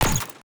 UIClick_Menu Strong Metal Rustle 03.wav